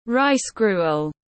Cháo hoa tiếng anh gọi là rice gruel, phiên âm tiếng anh đọc là /raɪs ˈɡruː.əl/
Rice gruel /raɪs ˈɡruː.əl/